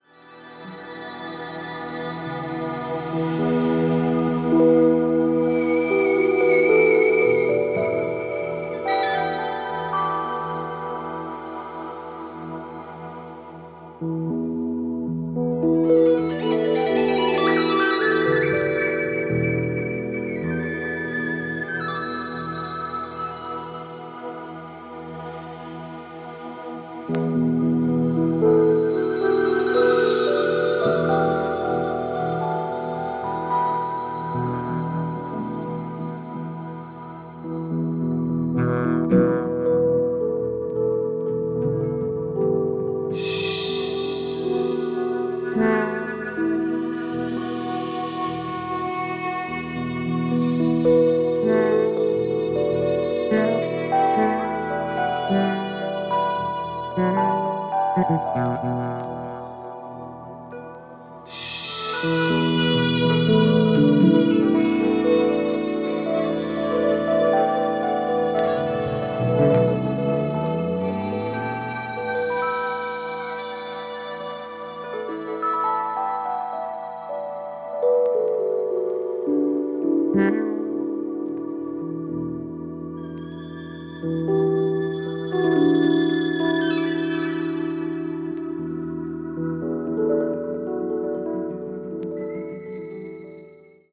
二人の透明感のあるサウンドは心地良いですね～。